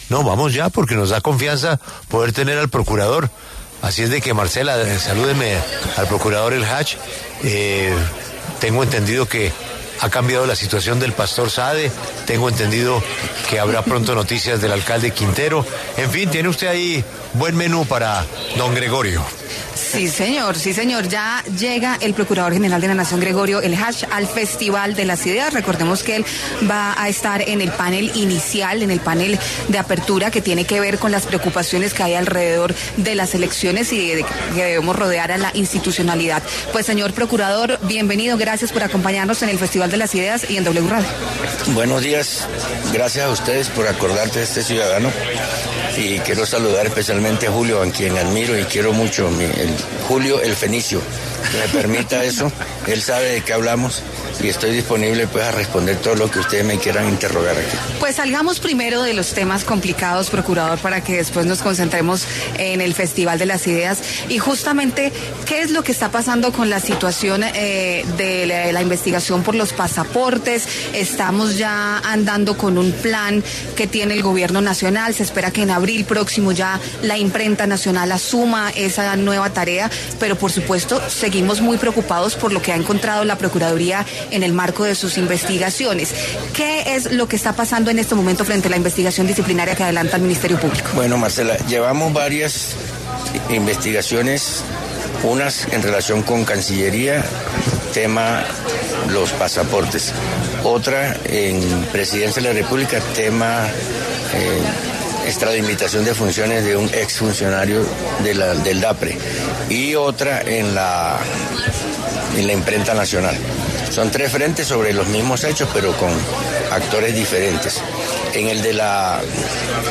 Desde el Festival de las Ideas, el procurador general, Gregorio Eljach, habló sobre varias investigaciones que competen a la entidad que él dirige.